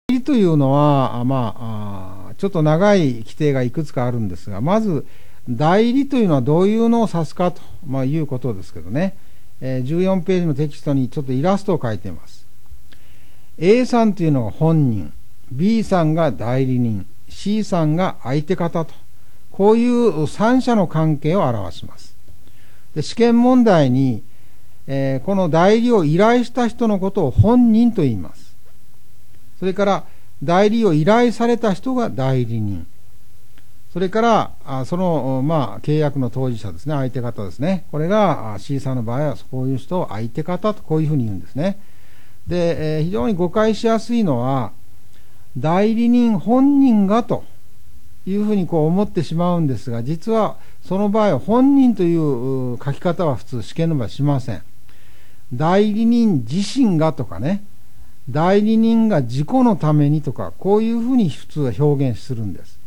本校主任講師が、授業中の録音でなく、特に、通信コースの受講生のために吹き込んだ、オリジナル教材です。
授業中の録音と異なり、雑音も少なく、簡素に講義していますから受講しやすくなっています。